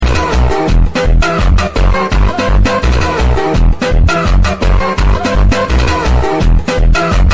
tiktok funny sound hahaha